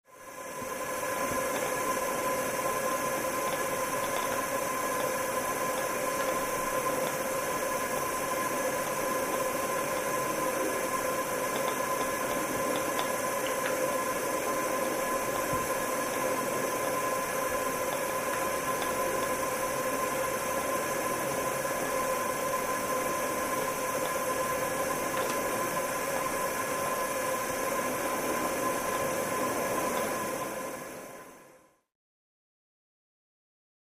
Pipes: Water Hissing Through, With Clicks.